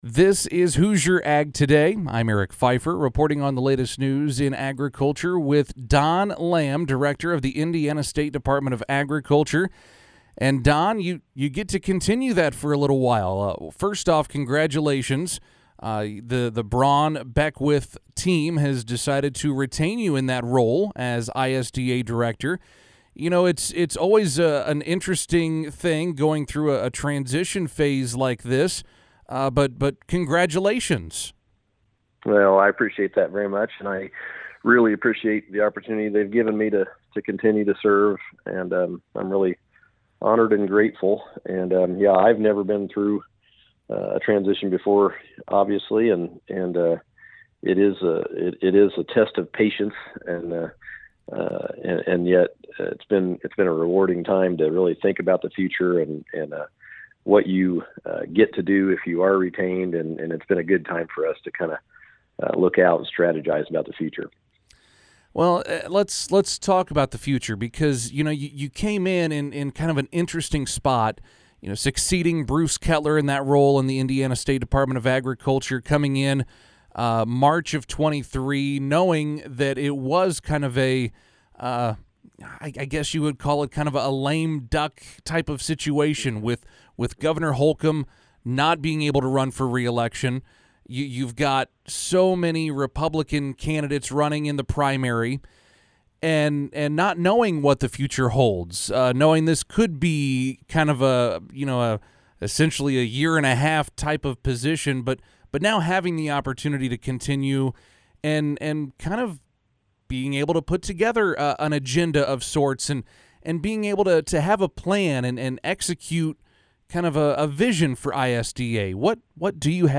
You can hear the full HAT interview with Lamb about his vision for ISDA and the working relationship with the Braun/Beckwith administration below.